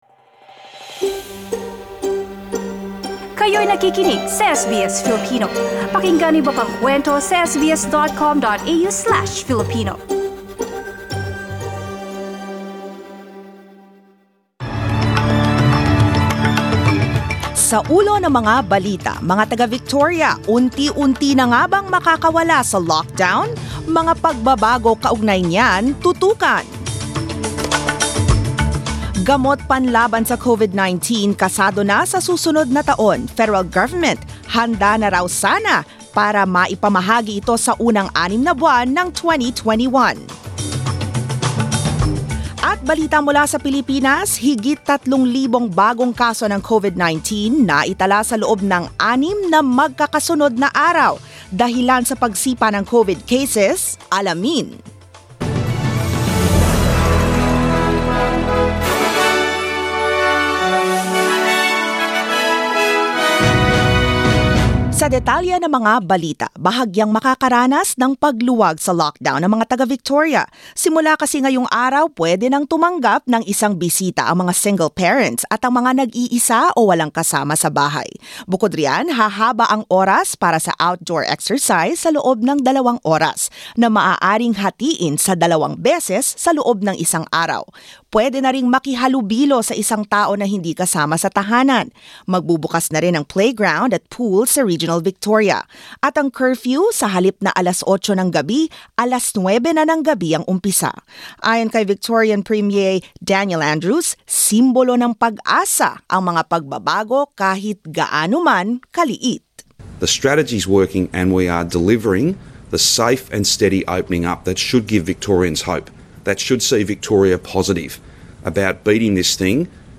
SBS News in Filipino, Monday 14 September